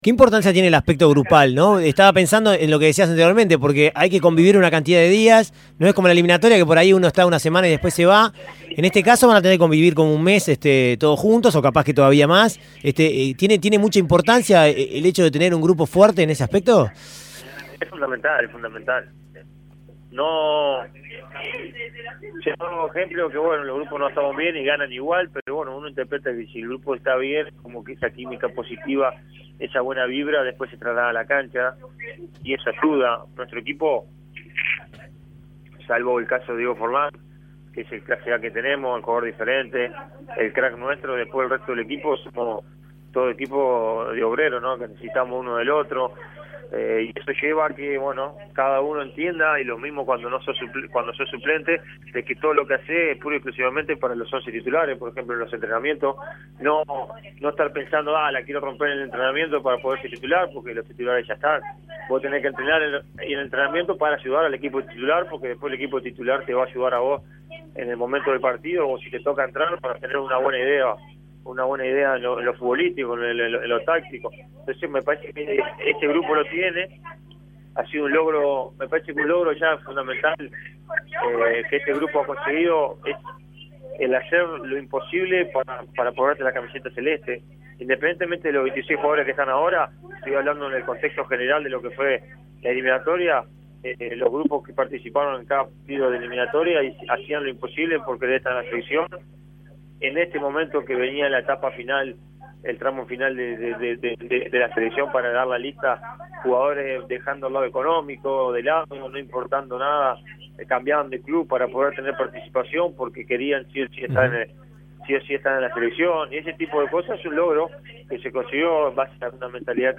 Sebastián Abreu conversó el domingo por la tarde con 13 a 0. La evolución del fútbol brasileñó, el Mundial de Sudáfrica y la selección uruguaya fueron alguno de los temas que trató el delantero celeste.